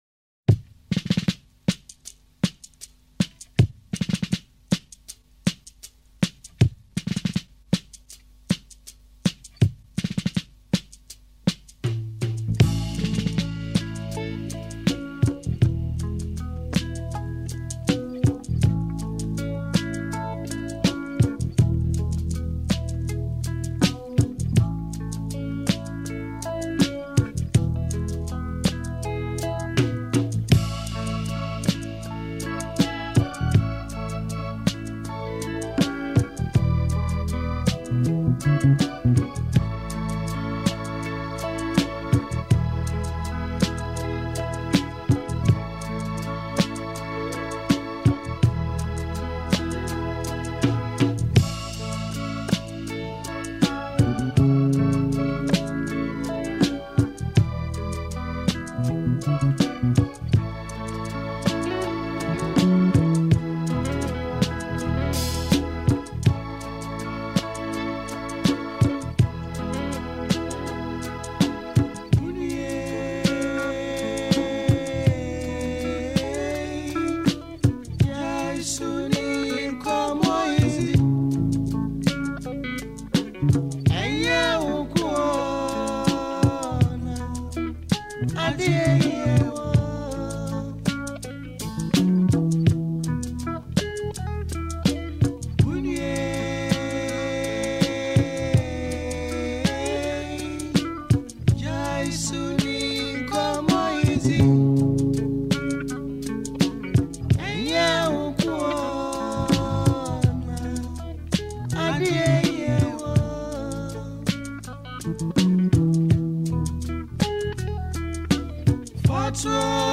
With its vibrant instrumentation and cultural depth